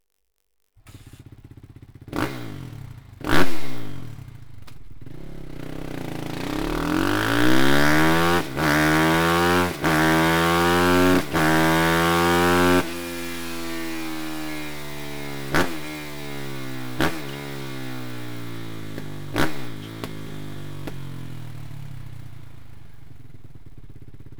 Akrapovic Evolution Line (Titanium) Titan-Komplettanlage mit Endschalldämpfer mit Titan-Hülle und Titan-Endkappe, ohne Straßenzulassung; für Kawasaki
Sound Akrapovic Komplettanlage